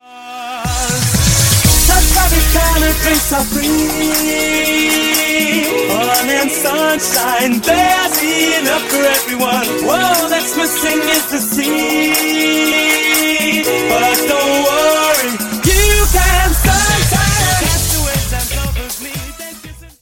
Dj Intro Outro